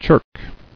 [chirk]